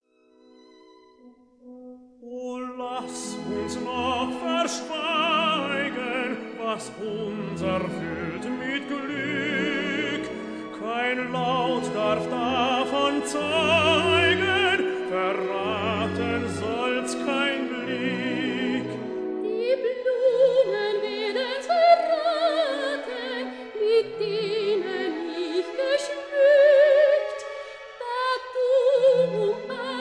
tenor
soprano
Stereo recording made in Berlin,